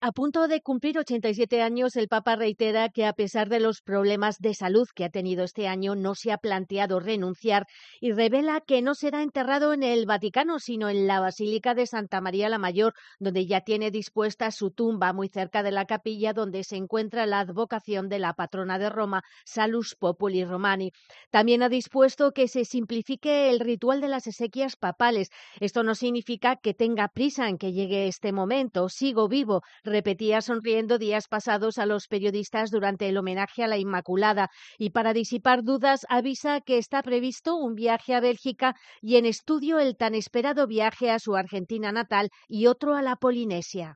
En una entrevista con la periodista Valentina Alazraki adelantó que, como ya sucedió con el funeral del Papa Benedicto XVI, el también simplificará las...